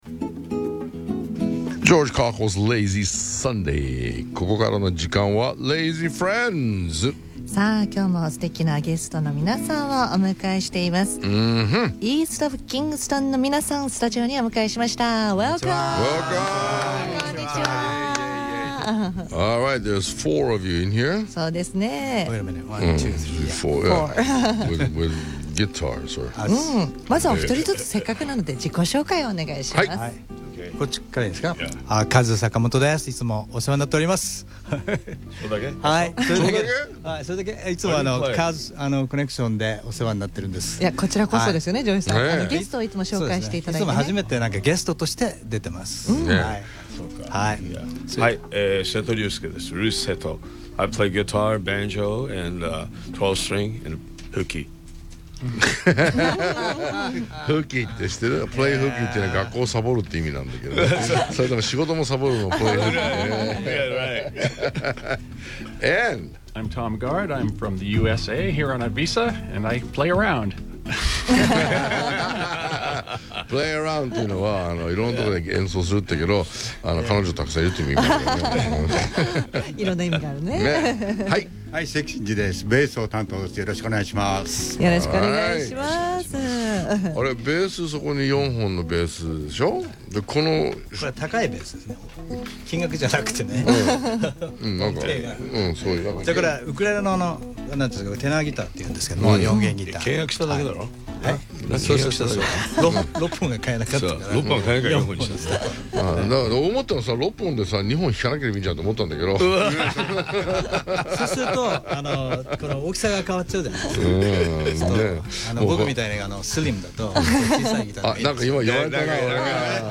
英語も飛び交うトークも含む内容をカットなしで こちらからお聴きになれます。